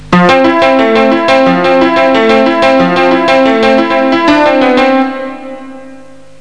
1 channel
PianoMelody3.mp3